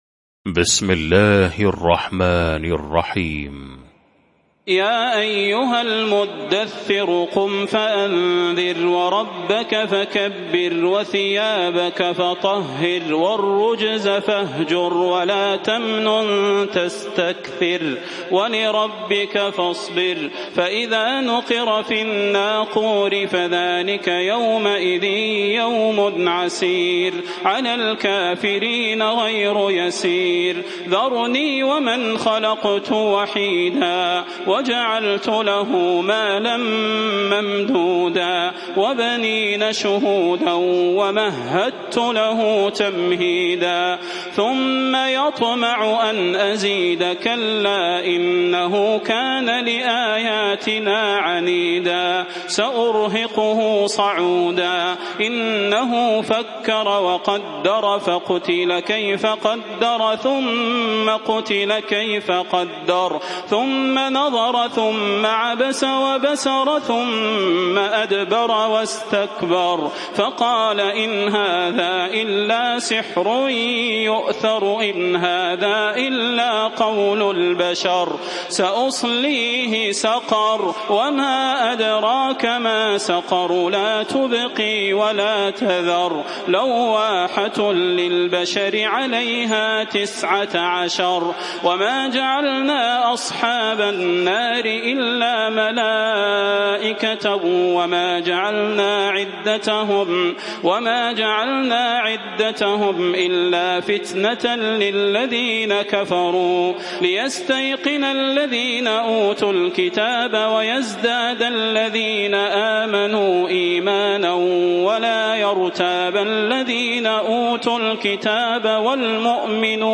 المكان: المسجد النبوي الشيخ: فضيلة الشيخ د. صلاح بن محمد البدير فضيلة الشيخ د. صلاح بن محمد البدير المدثر